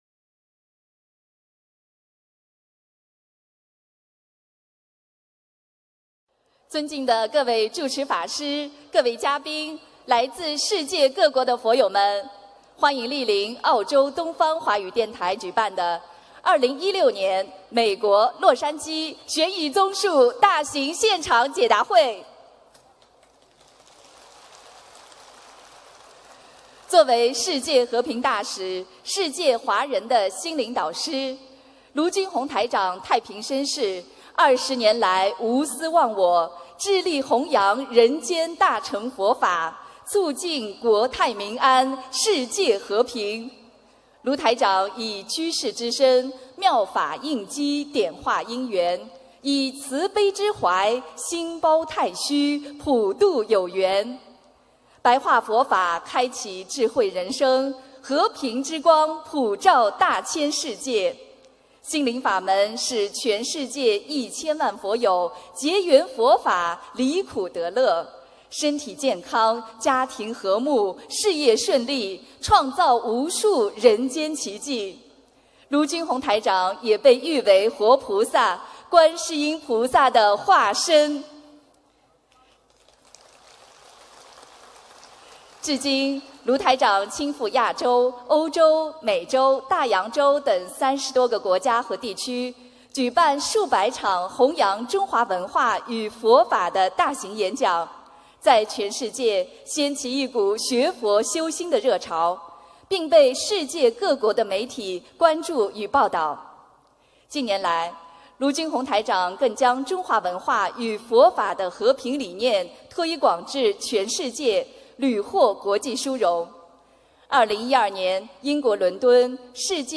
2016年9月11日美国洛杉矶解答会开示（视音文图） - 2016年 - 心如菩提 - Powered by Discuz!